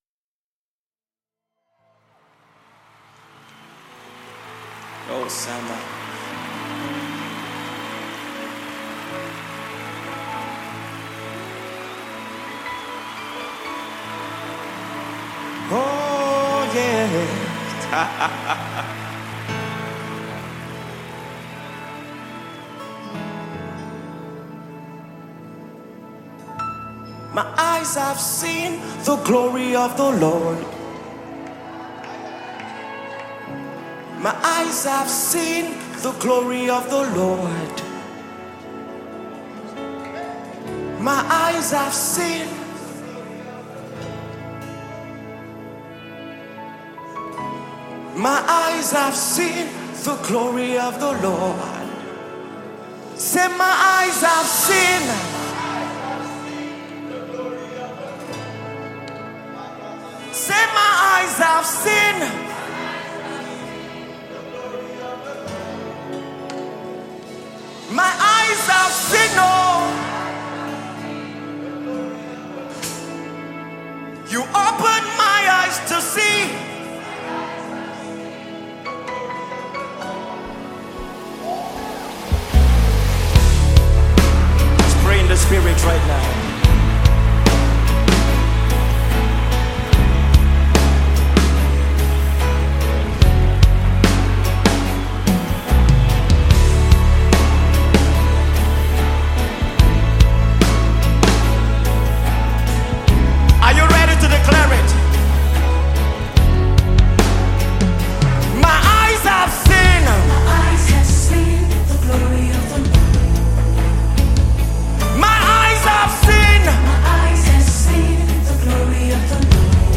a powerful sound of worship